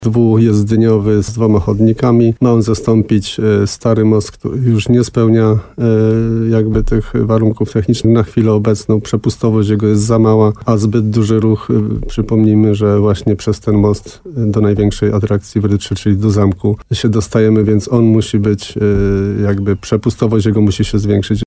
O technicznych szczegółach planowanego mostu mówił w radiu RDN Nowy Sącz wójt gminy Rytro Jan Kotarba.